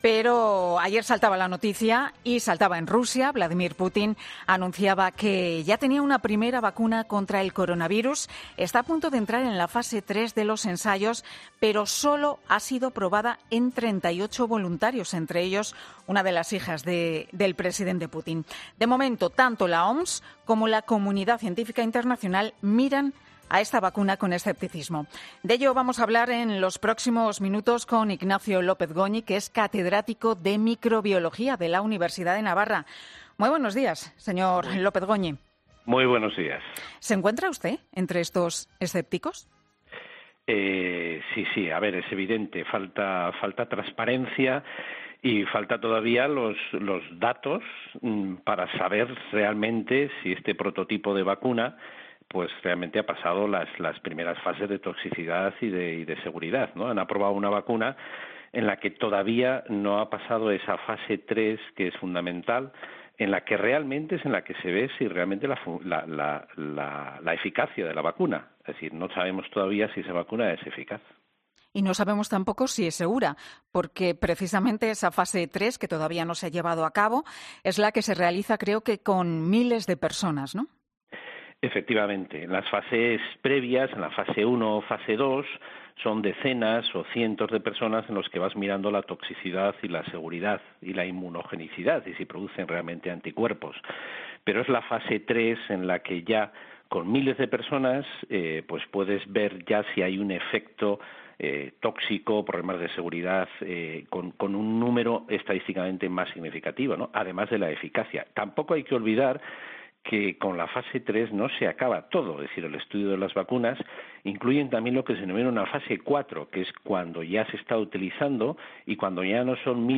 Sobre esto hemos hablado esta mañana en 'Herrera en COPE'